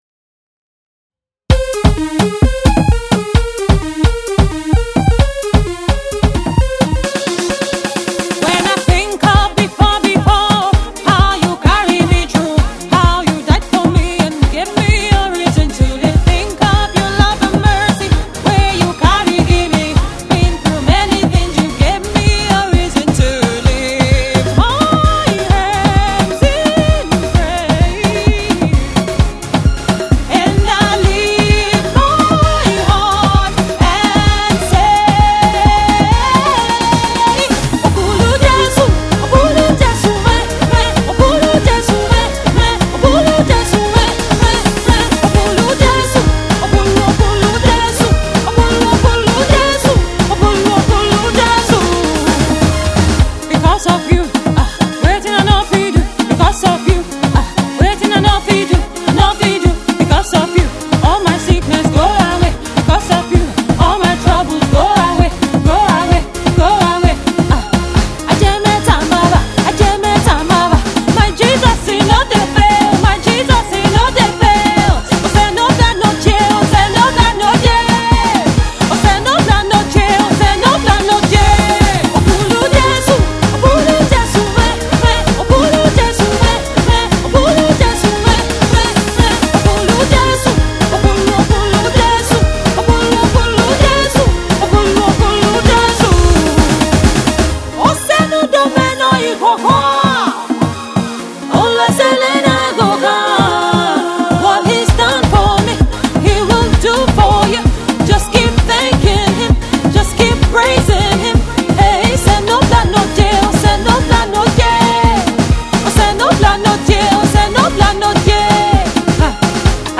Gospel Songstress
inspiring vocal rendition